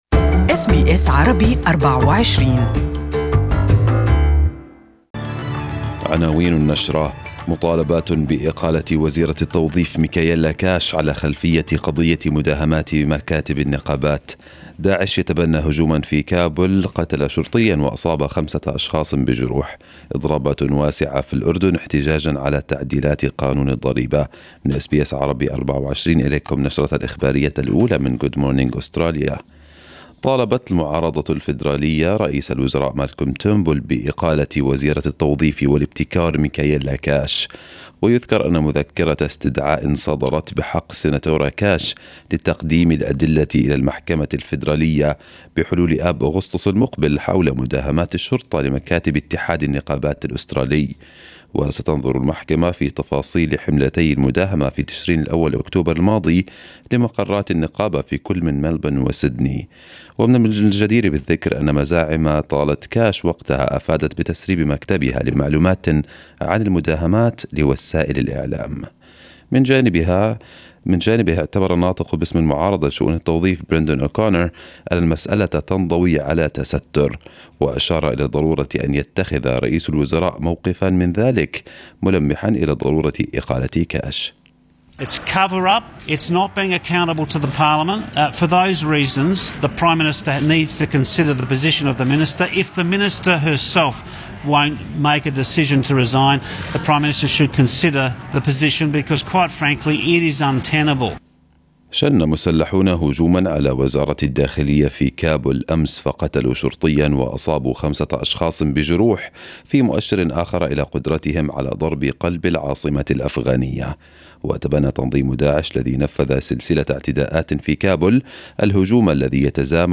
Arabic News Bulletin 31/05/2018